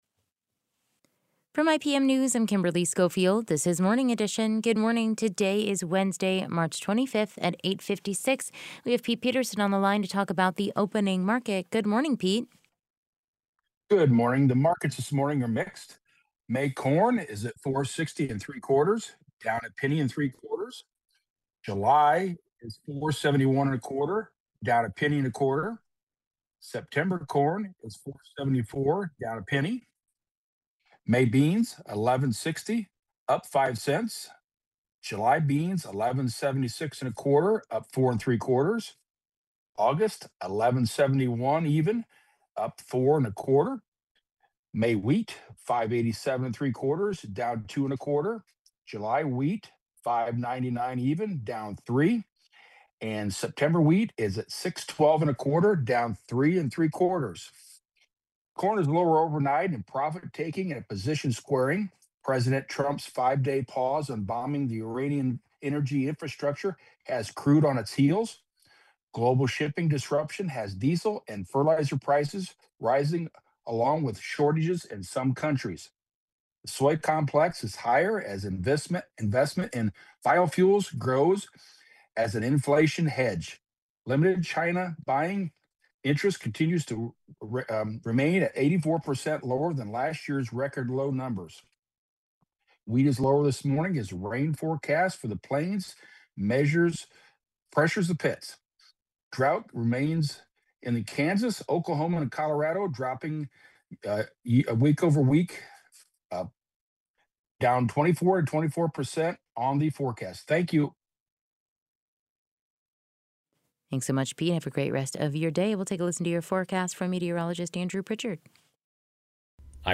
Opening Market Report